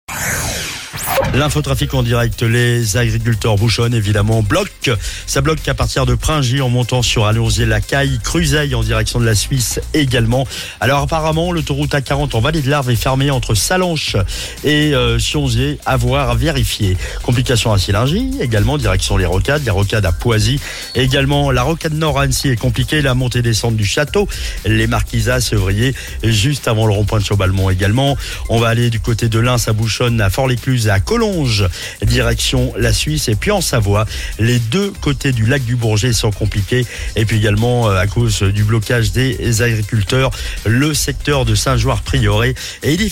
Info trafic